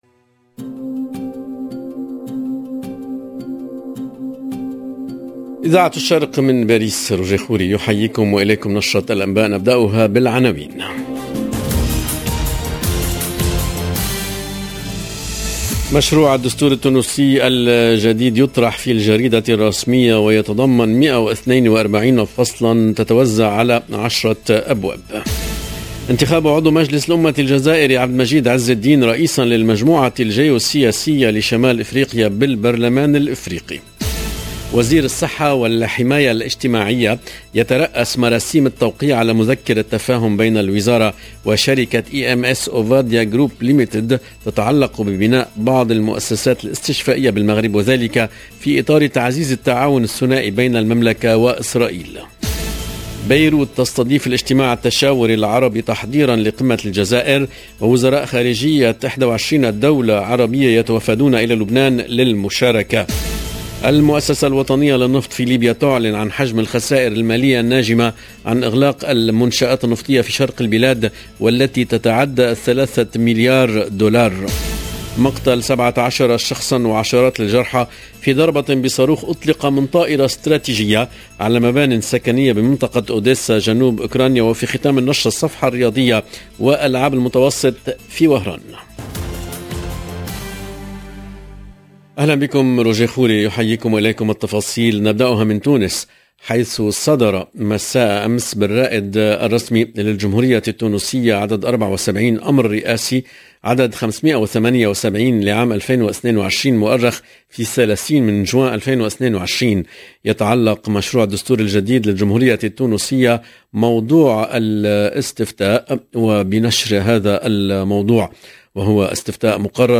LE JOURNAL EN LANGUE ARABE DE LA MI-JOURNEE DU 1/07/22